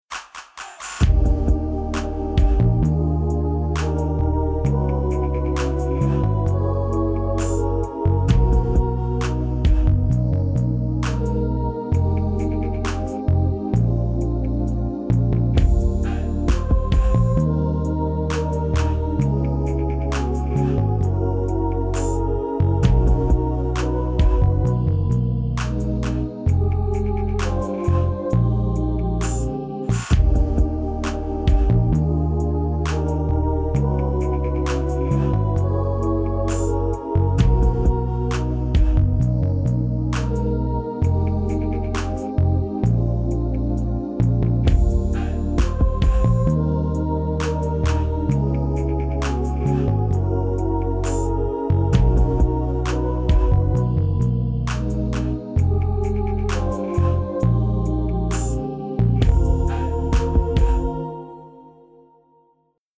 Akkordprogression med modulation og gehørsimprovisation:
Lyt efter bassen, der ofte spiller grundtonen.
C instrument (demo)